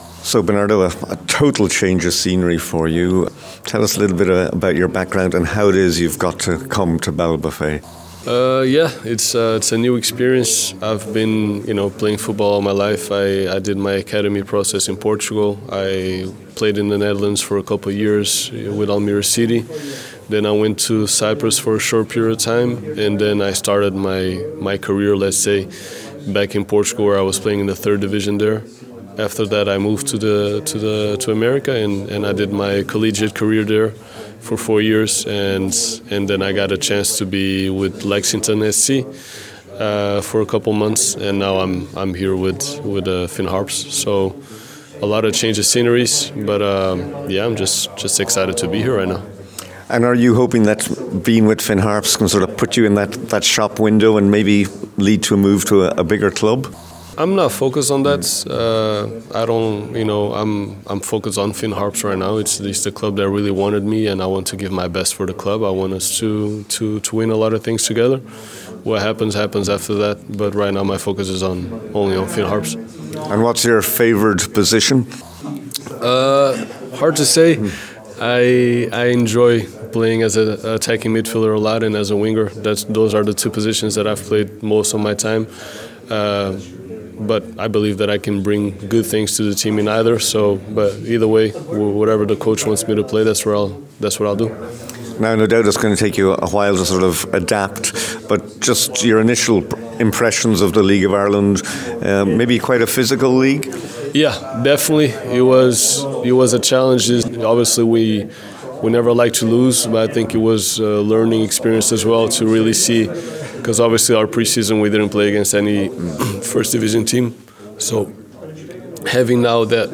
At Monday’s press conference at Jackson’s Hotel in Ballybofey